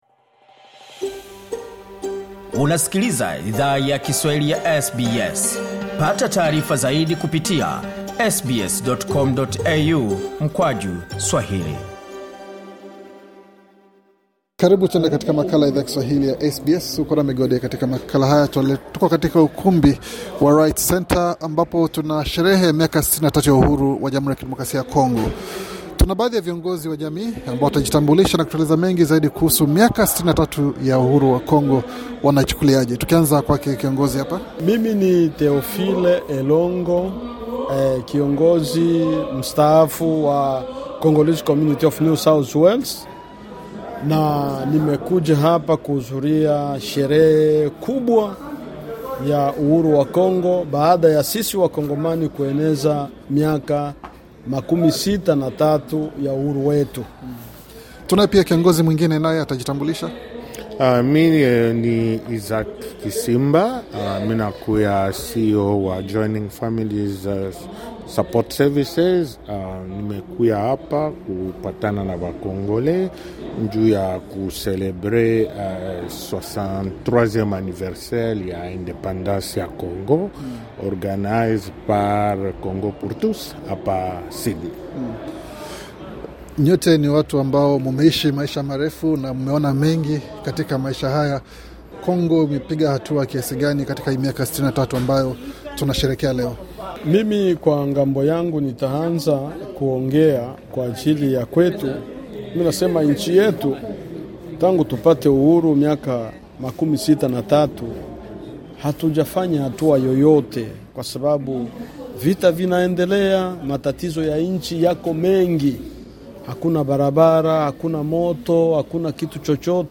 Katika mazungumzo na SBS Swahili, baadhi ya viongozi wa jumuiya hiyo, walifunguka kuhusu hatua ambazo taifa lao limepiga tangu lipate uhuru kutoka wakoloni.